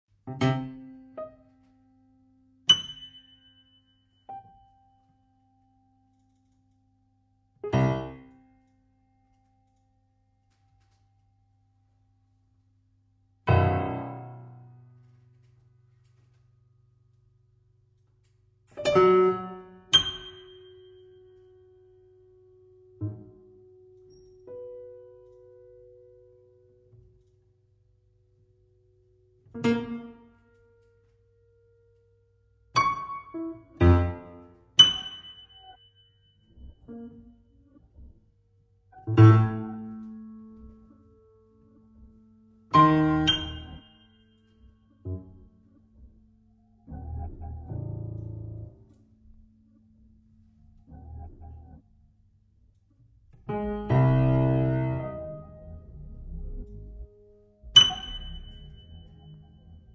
pianoforte, oggetti
live electronics
Un'esecuzione inconsueta per un organico atipico
L'intero album è stato registrato dal vivo